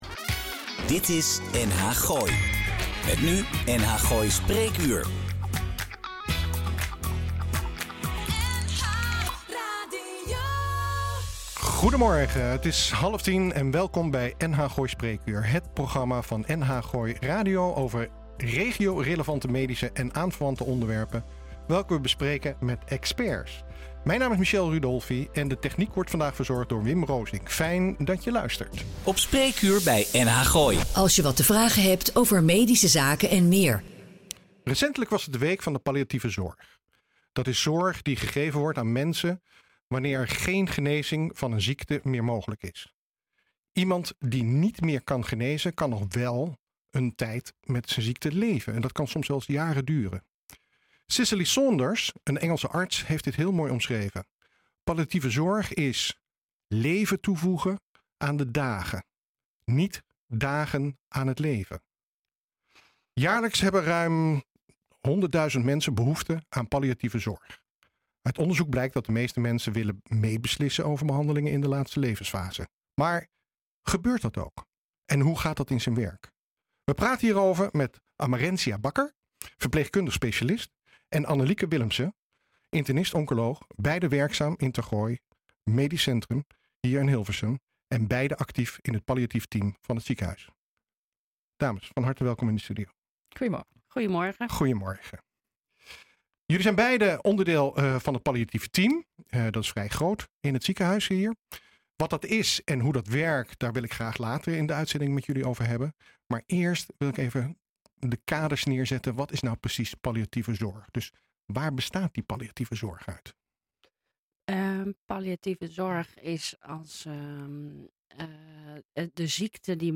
NH Gooi is de streekomroep voor Hilversum, Huizen, Blaricum, Eemnes en Laren.